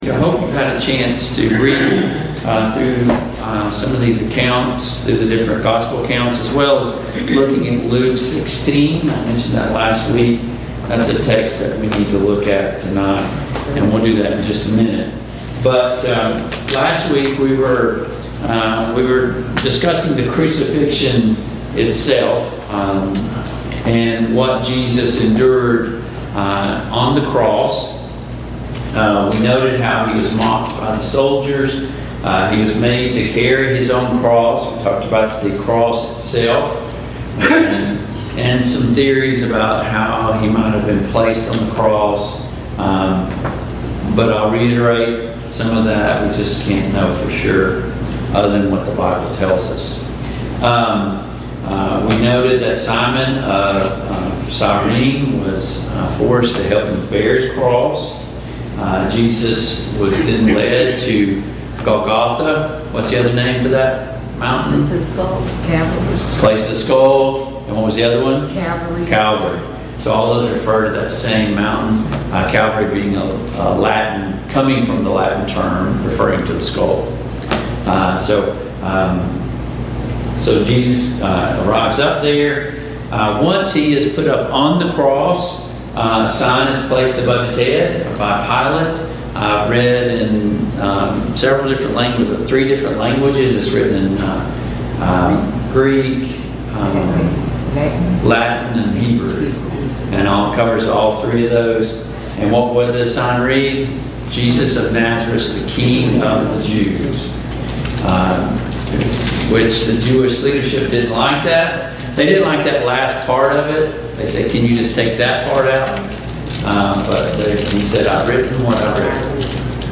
Service Type: Mid-Week Bible Study Download Files Notes Topics: Death and Hades , Hades , Paradise , The Crucifixion « 5.